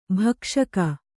♪ bhakṣaka